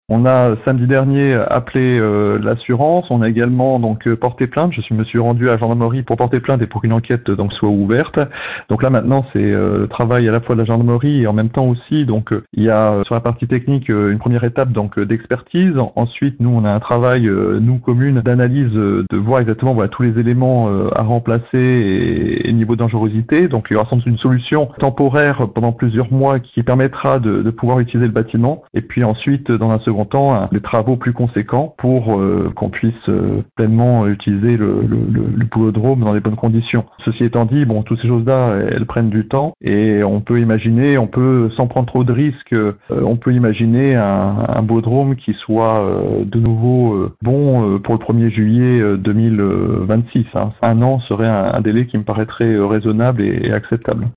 Toujours est-il que le maire Thibault Brechkoff a fait savoir qu’il a porté plainte.